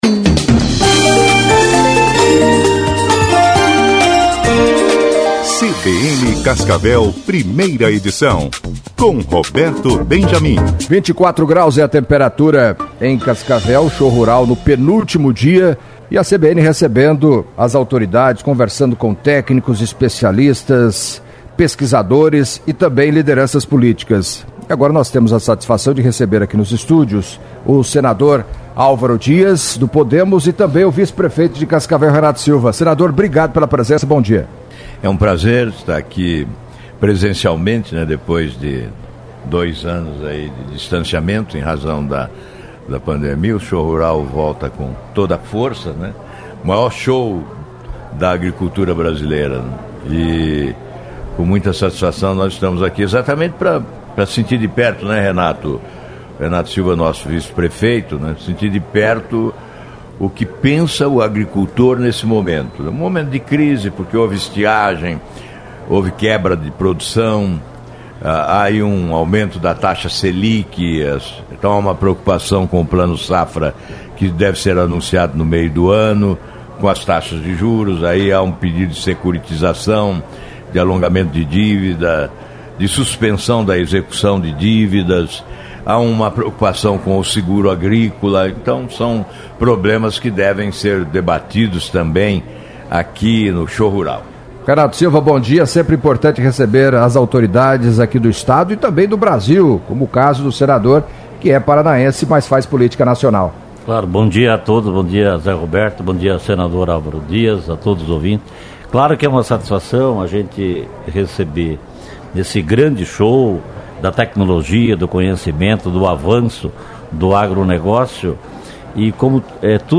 Em entrevista à CBN Cascavel nesta quinta-feira (10) o senador Alvaro Dias, pré-candidato ao Senado, falou da possibilidade de ser candidato ao governo do Paraná, caso a coligação com o PSD do governador Ratinho Júnior "fracasse".